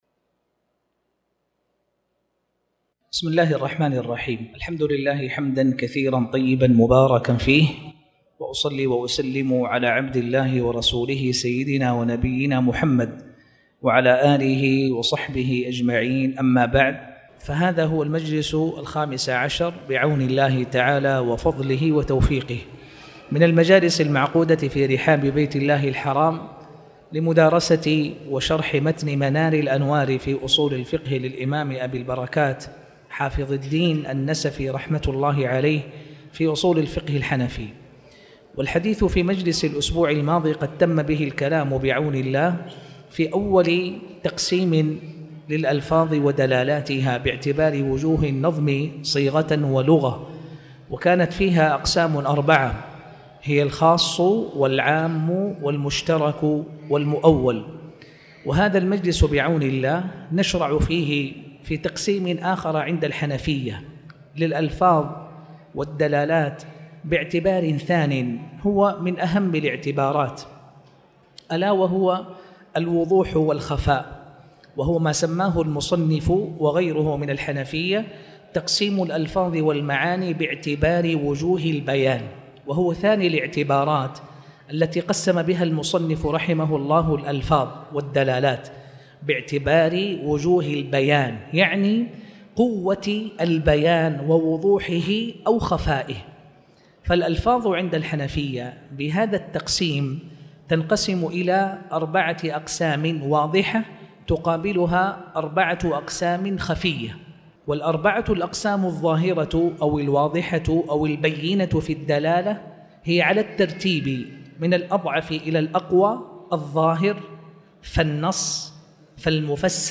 تاريخ النشر ٢٣ ربيع الثاني ١٤٣٩ هـ المكان: المسجد الحرام الشيخ